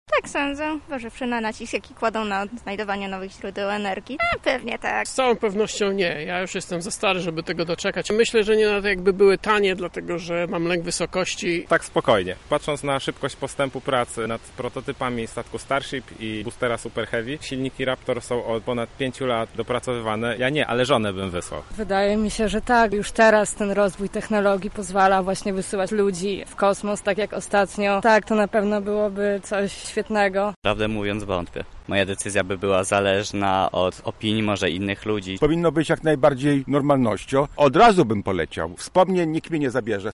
Zapytaliśmy bywalców Ogrodu Saskiego, czy ich zdaniem tego typu podróże będą normalnością za ich życia oraz, czy zdecydowaliby się w nich uczestniczyć, jeśli mogliby zrobić to jutro.
sonda